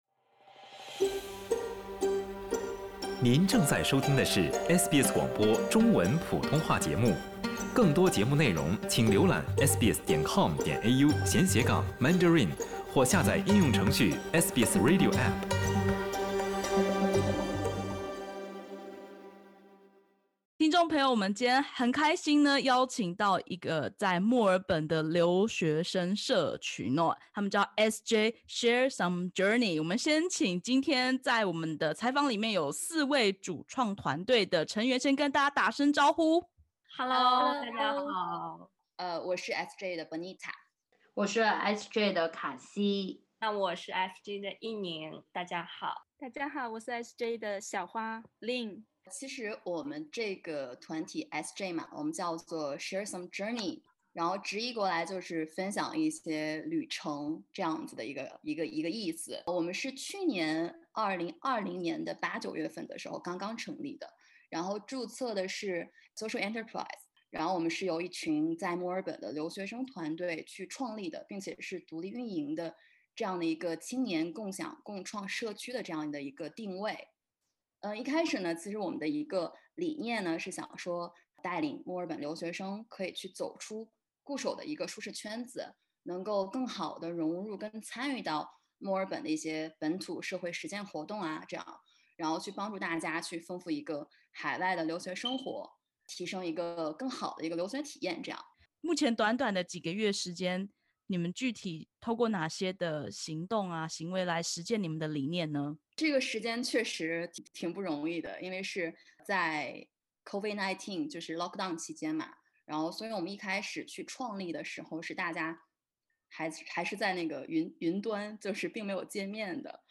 社会企业Share some Journey立志於给予留学生平台，打造青年共享和共创的社区。点击首图收听采访音频。